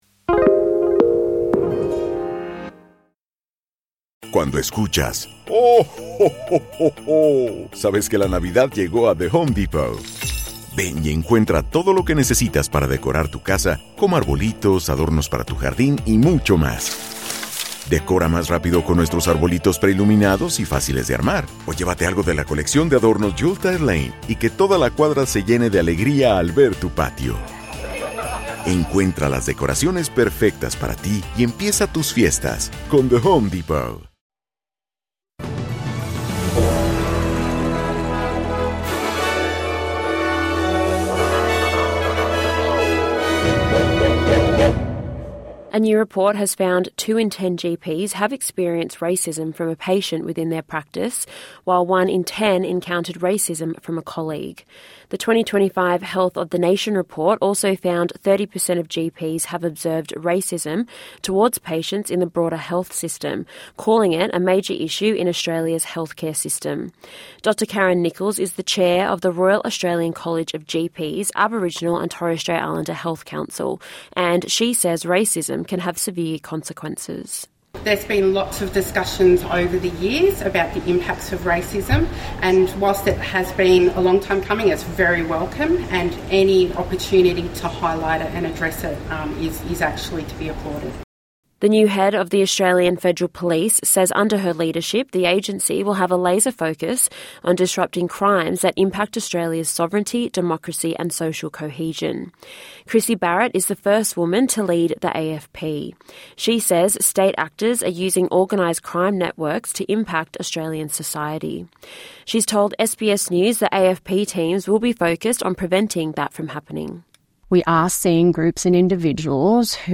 The latest in National and International news for Wednesday 8th October.